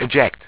1 channel
w3_eject.wav